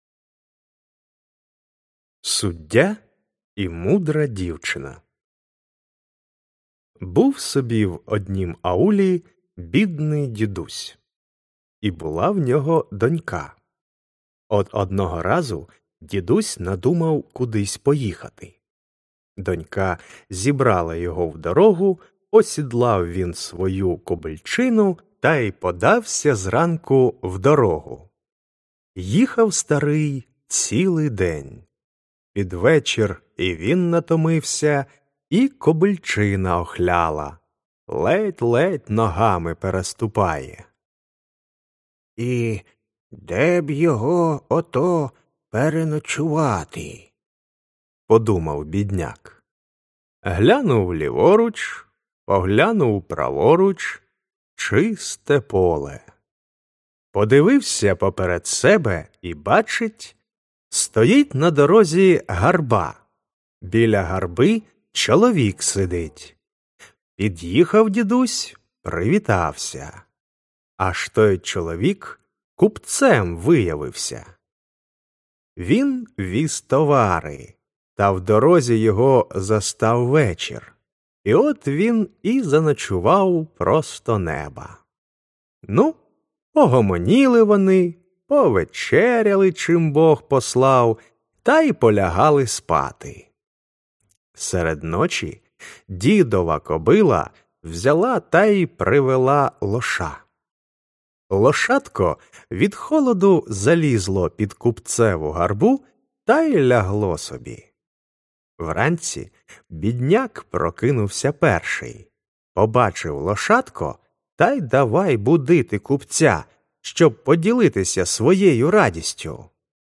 Аудіоказка Суддя і мудра дівчина